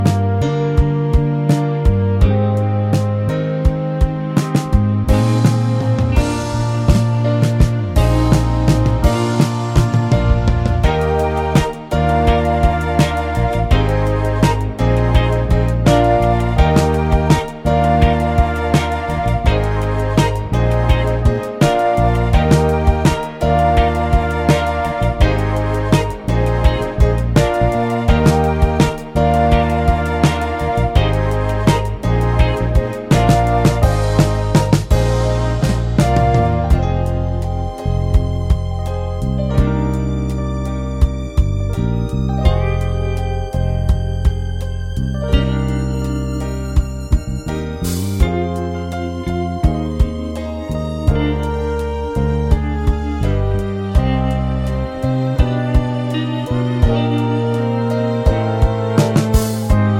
no Backing Vocals Duets 3:33 Buy £1.50